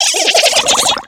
Cri de Cradopaud dans Pokémon X et Y.